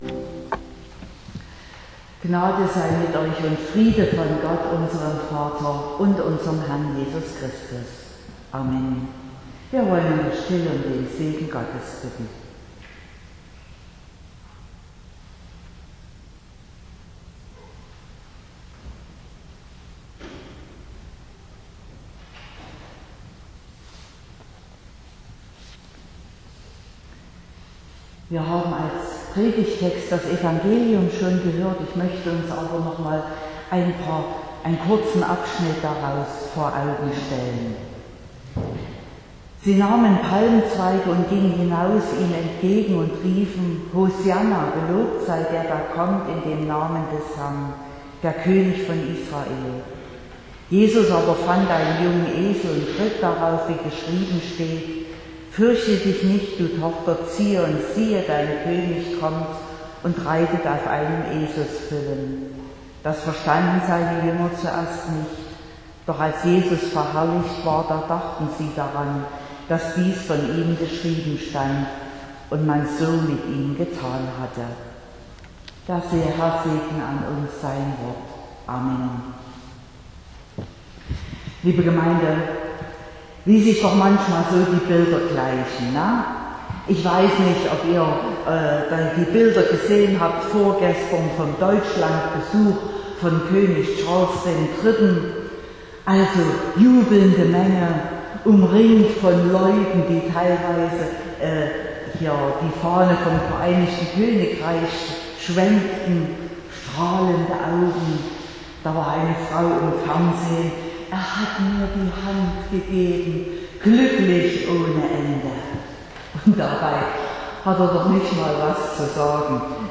02.04.2023 – Gottesdienst
Predigt (Audio): 2023-04-02_Gott_erfuellt_nicht_alle_Wuensche_-_aber_alle_Verheissungen.mp3 (24,8 MB)